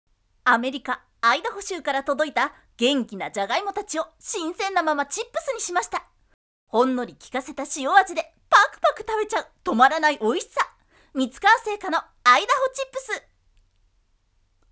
V O I C E